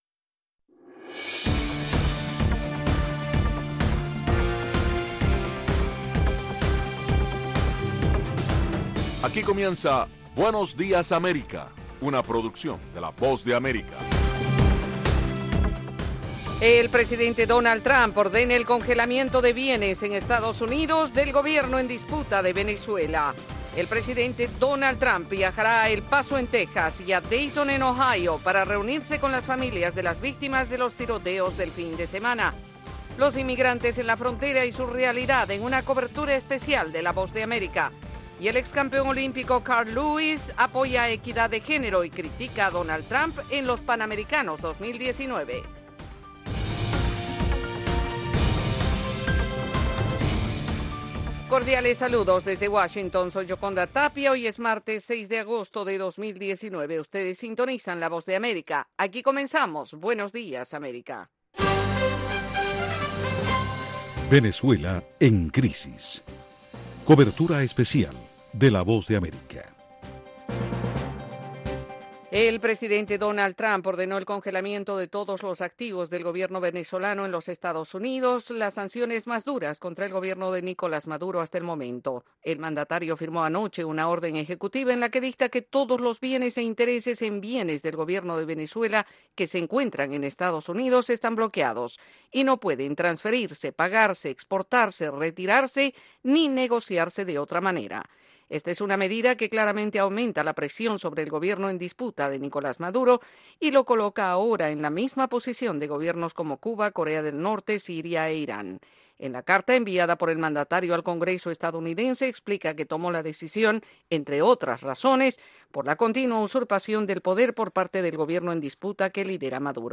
Información ágil y actualizada en las voces de los protagonistas con todo lo que sucede en el mundo, los deportes y el entretenimiento.